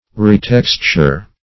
Retexture \Re*tex"ture\, n.
retexture.mp3